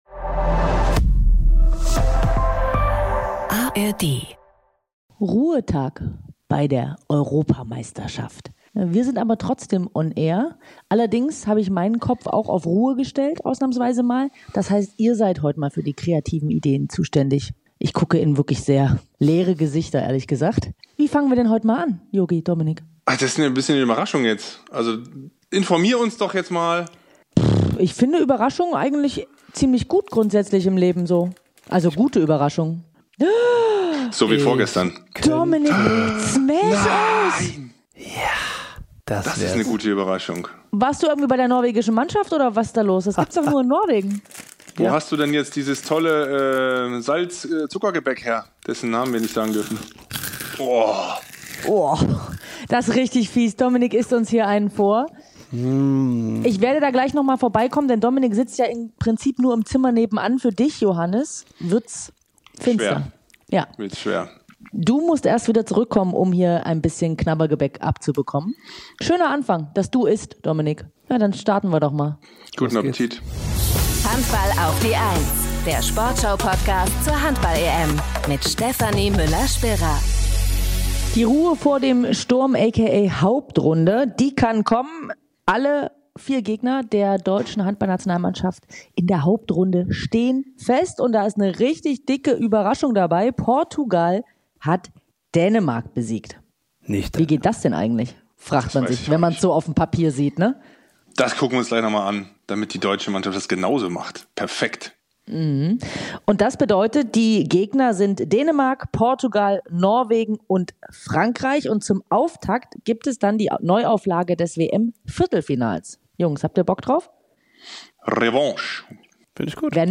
Im exklusiven Interview verrät er, wie er an einem freien Tag am besten Kraft tankt und welche Gesellschaftsspiele bei der Mannschaft dieses Jahr besonders beliebt sind.